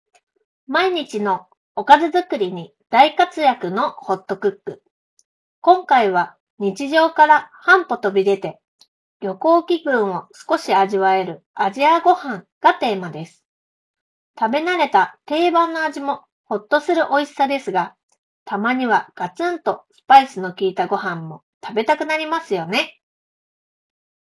実際に録音に使ってみると、空調音や周囲の環境ノイズを効果的に抑えながら、装着者の声だけをしっかり拾い上げてくれました。
▼Clip1のマイクで拾った音声単体
録音した音声を確認すると、わずかに反響感はあるものの、聞き取りづらさはなく、実用面で気になるレベルではありません。むしろ声は十分クリアで、Web会議用途でも、専用マイクの代替として問題なく使えるクオリティだと感じました。